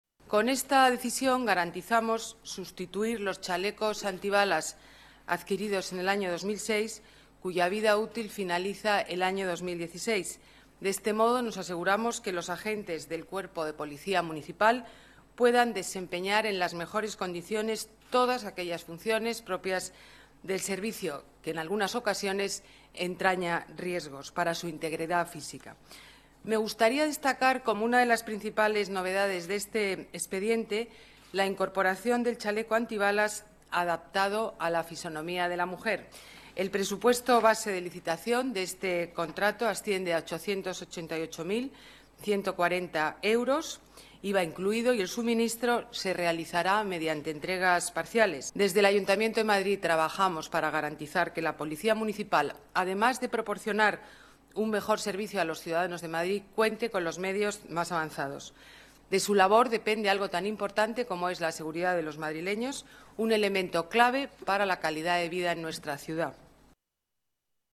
Nueva ventana:Declaraciones alcaldesa Madrid, Ana Botella: Junta de Gobierno, nuevos chalecos antibala para la PM